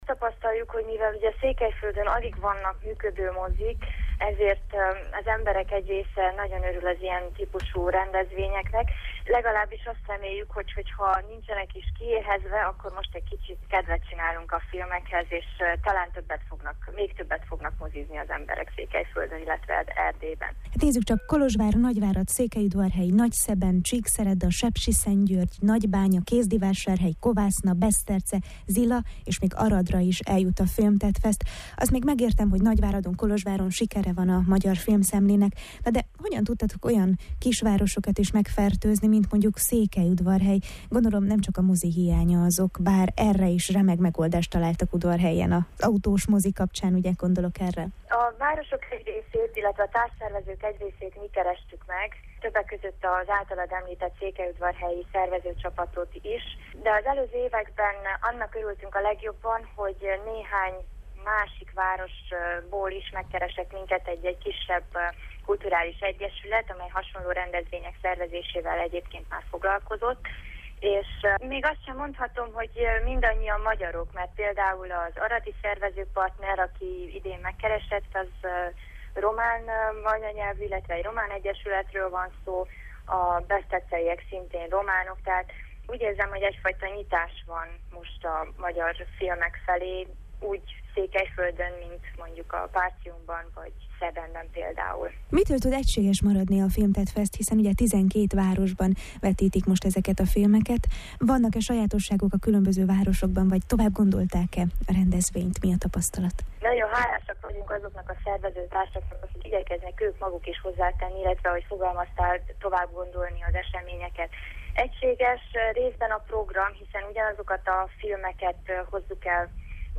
A KULTÚRPRESSZÓ vendége